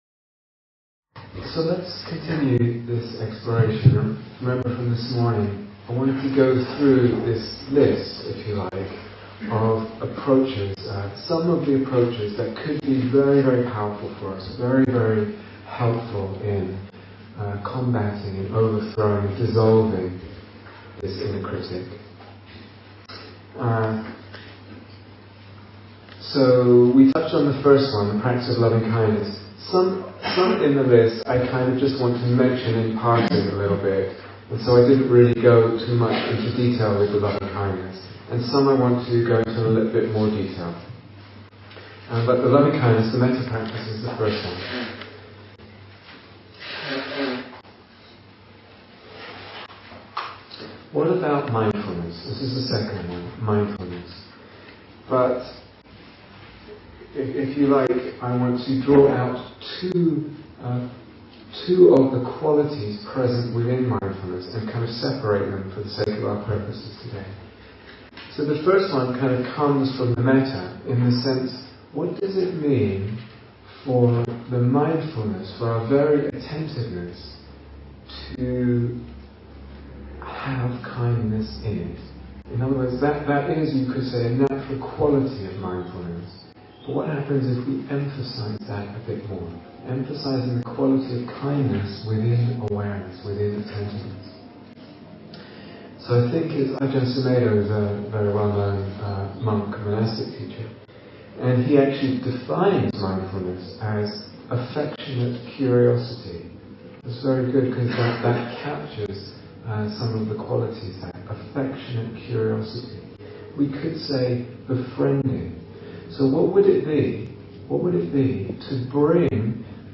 Ending the Inner Critic (2) Listen to AI-enhanced audio (noise reduced) Download 0:00:00 46:27 Date 4th December 2010 Retreat/Series Day Retreat, London Insight 2010 Transcription So let's continue this exploration.